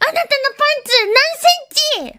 Worms speechbanks
Watchthis.wav